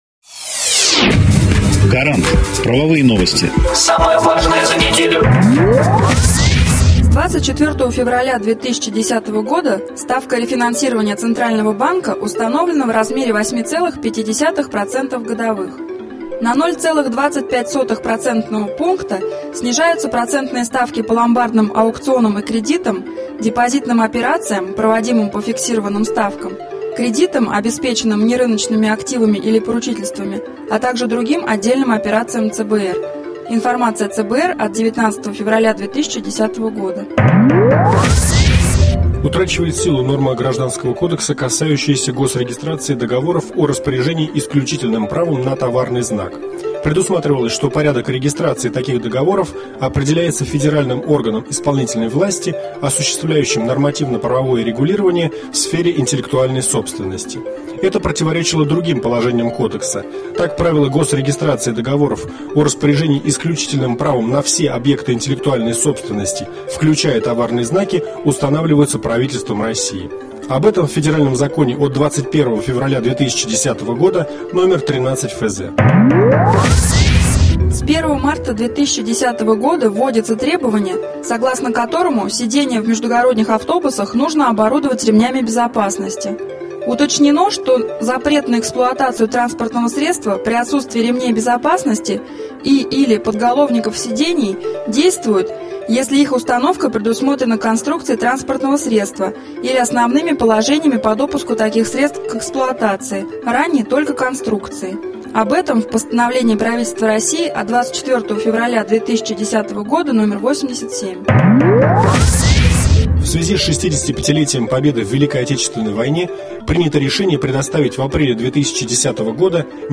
Аудионовости законодательства
Эксперты компании "Гарант" доступно и кратко рассказывают об актуальных законодательных нововведениях за последнюю неделю, акцентируя внимание на самом важном и интересном.